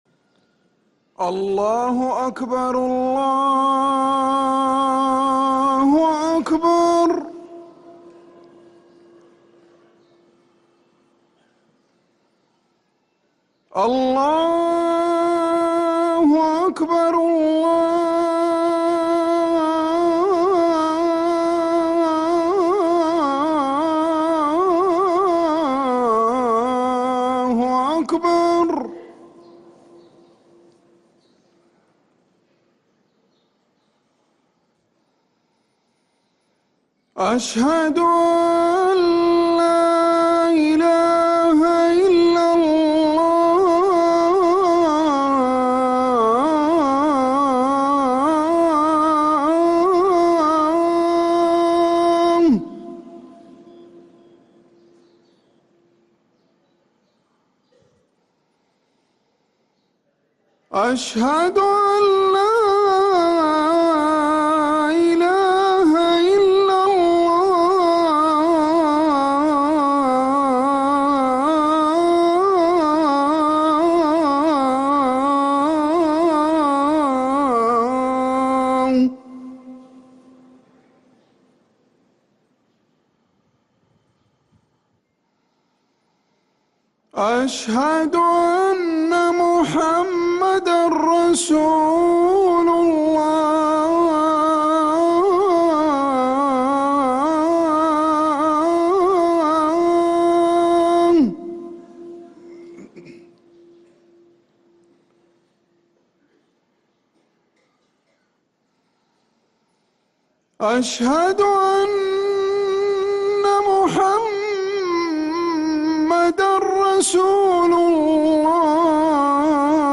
أذان الفجر للمؤذن عمر سنبل الجمعة 10 ربيع الأول 1446هـ > ١٤٤٦ 🕌 > ركن الأذان 🕌 > المزيد - تلاوات الحرمين